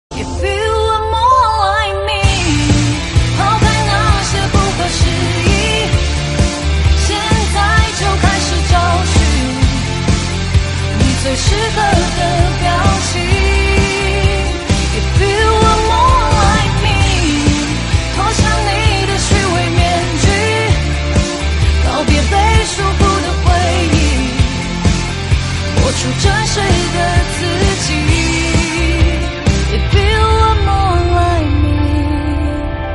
M4R铃声, MP3铃声, 华语歌曲 42 首发日期：2018-05-15 01:52 星期二